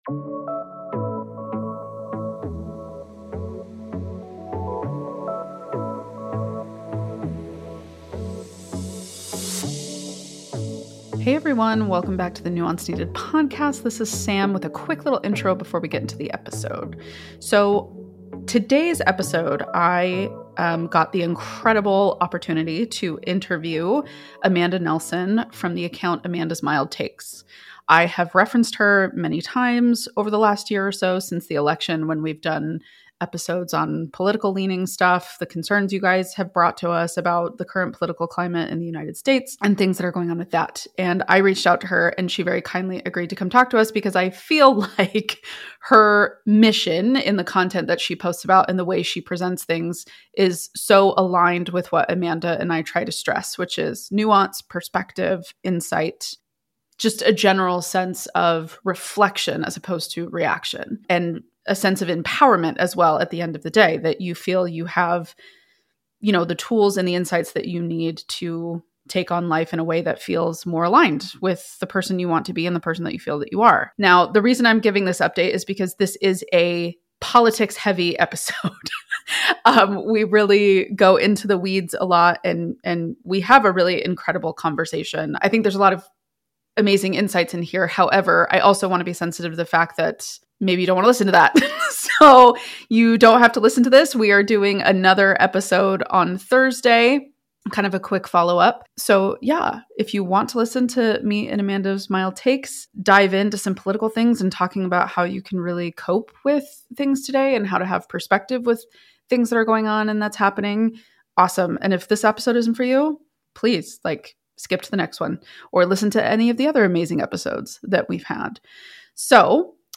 A conversation about finding your lane, protecting your mental health, and remembering that democracy requires participation!